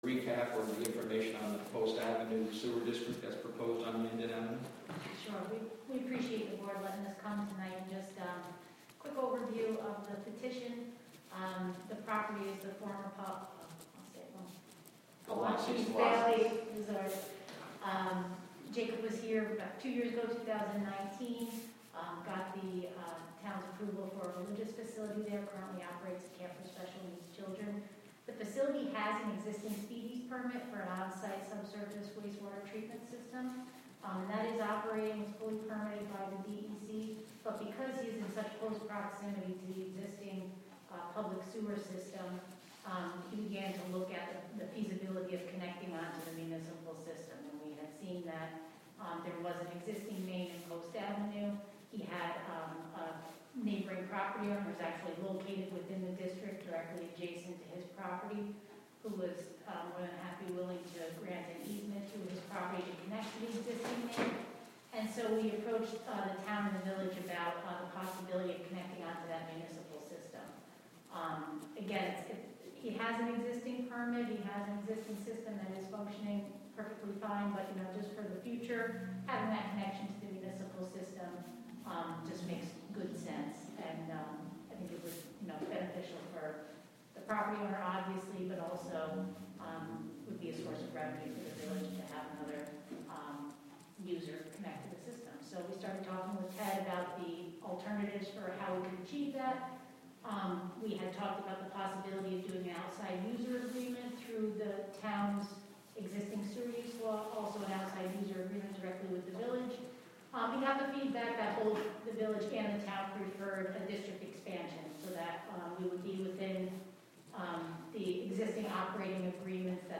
Live from the Town of Catskill: Town Board Committee Meeting December 15, 2021 (Audio)